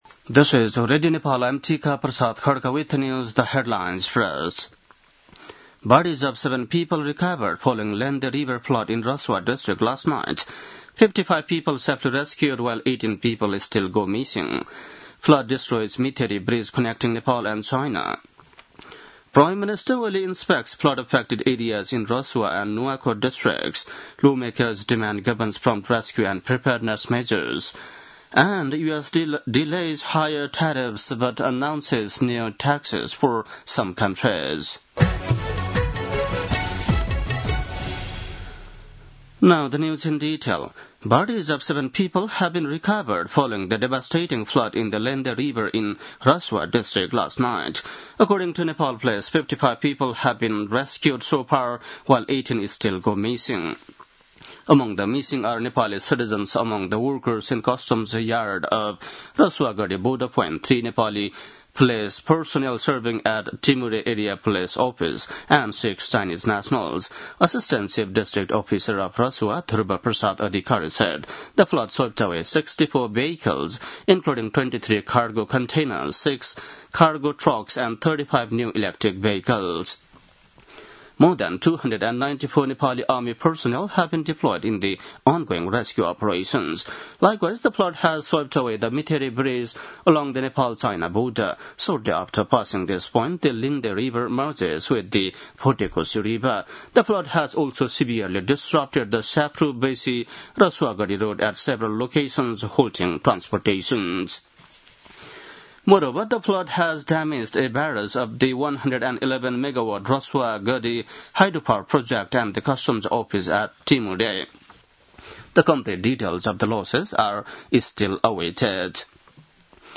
बेलुकी ८ बजेको अङ्ग्रेजी समाचार : २४ असार , २०८२
8-pm-english-news-3-24.mp3